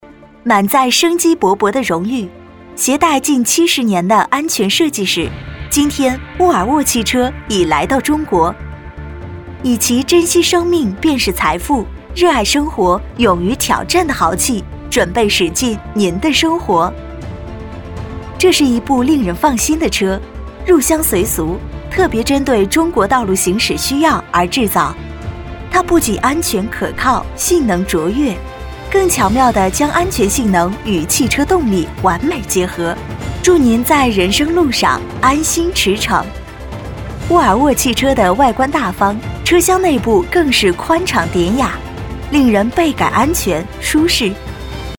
成熟知性 企业宣传配音
大气年轻女音，甜美。